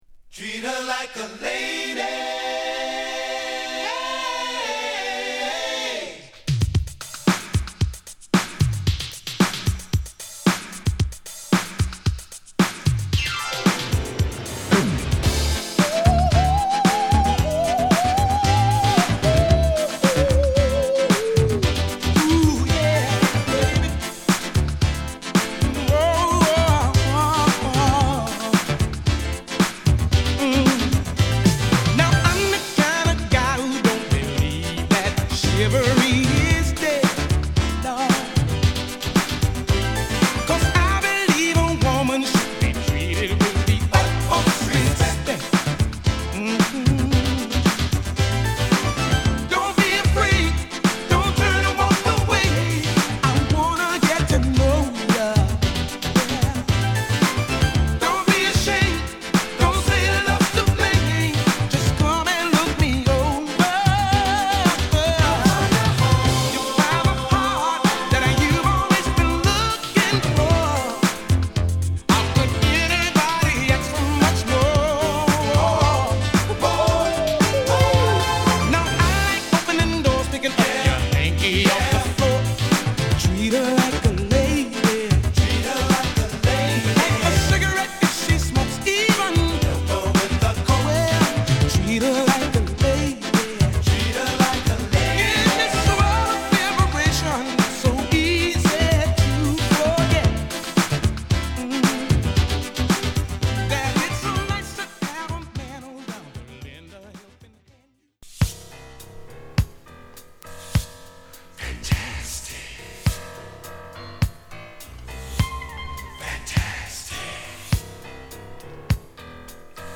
モータウンを代表する男性グループ